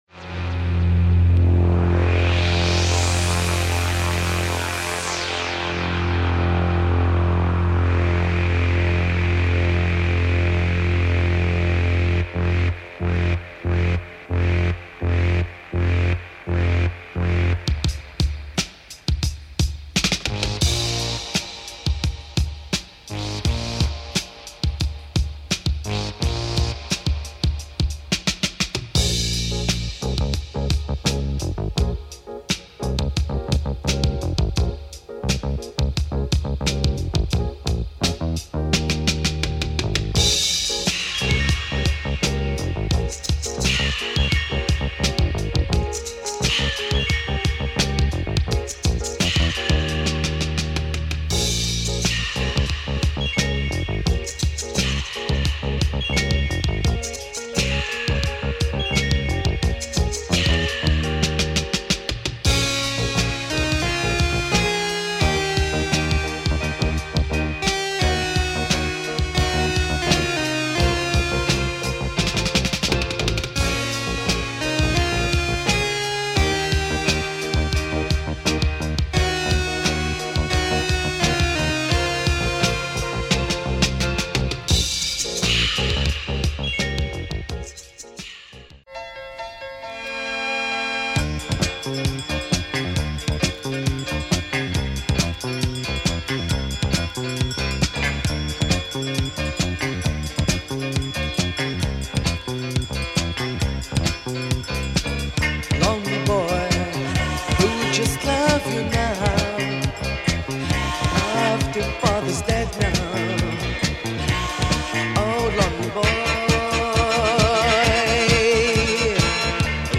Super rare and cult Lebanese electroid funk !